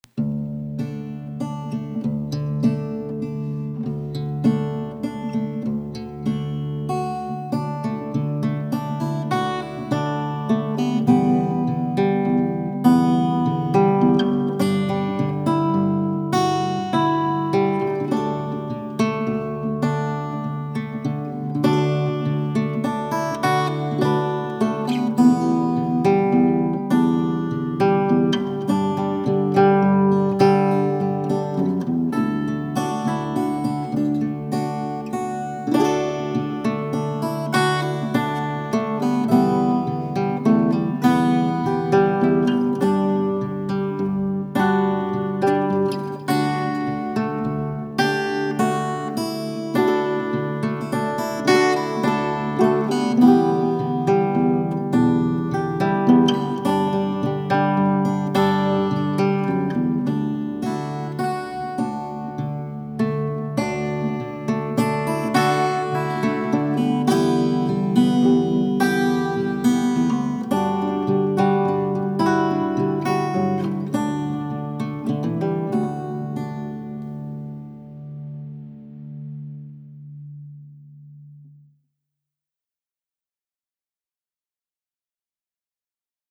Acoustic laidback guitar tune with a warm organic purity.